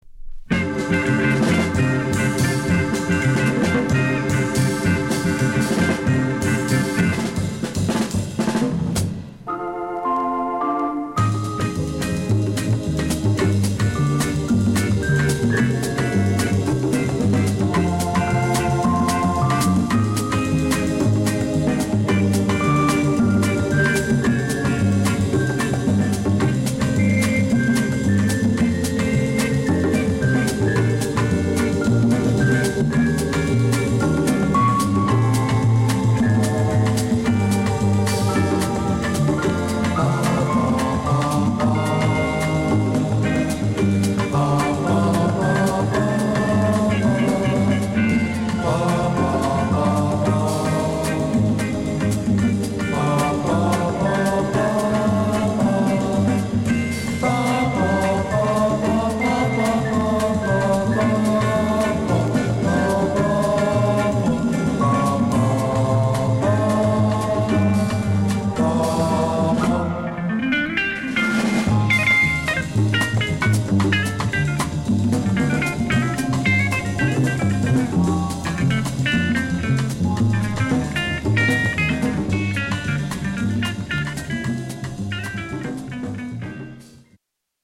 (60年代のブラジル盤はコンディション完璧な物を見つけるのは難しいので曲間等の多少のチリノイズは予めご了承ください。)